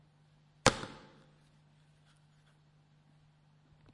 CA2音频列表 " 塑料Bang01
描述：在桌子上的塑料
Tag: 塑料